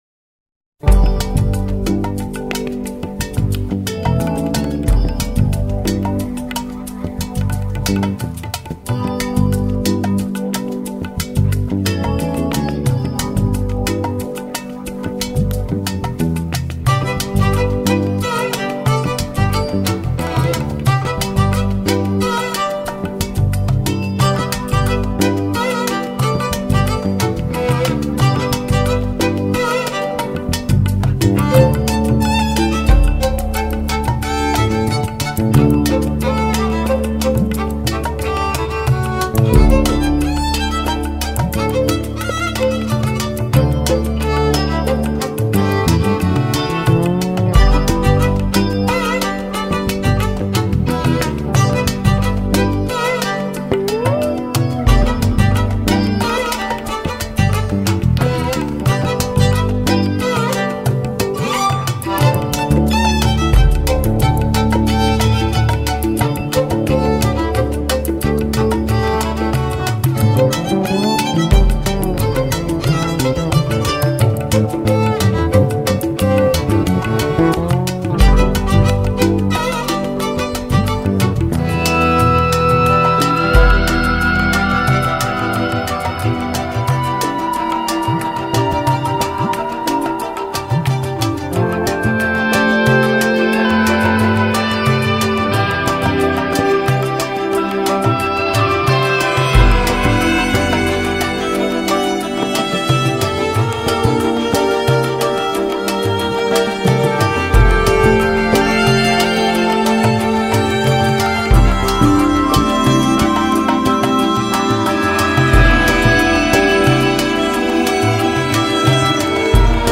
融合古典、摇滚、北欧乡村风、印度民族风、苏格兰曲风、柔情女声、吟游诗歌..等多元乐风
录音定位清楚、音质细腻清晰、音像深度及宽度精采可期
透过人声、小提琴、萨克斯风、双簧管、贝斯、吉他、钢琴、打击乐器、印度笛、竖笛、扬琴、